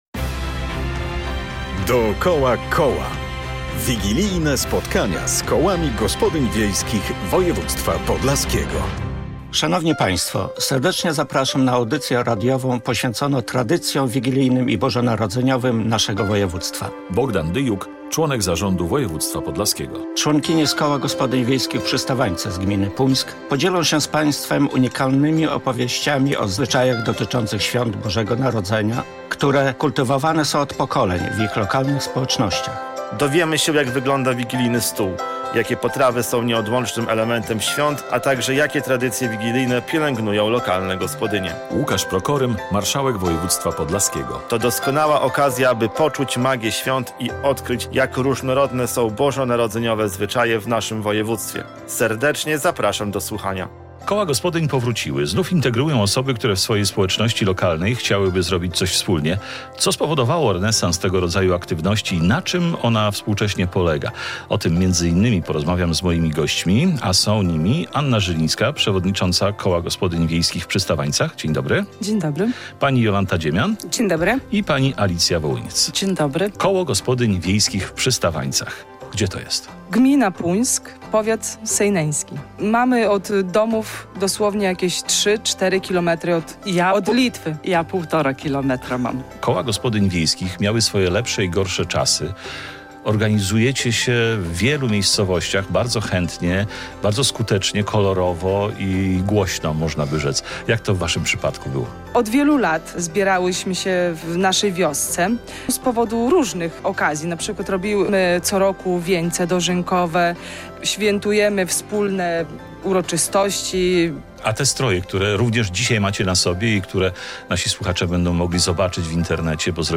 Naszymi gośćmi będą członkinie:
Koło Gospodyń Wiejskich "Przystawańce" z gminy Puńsk | Pobierz plik.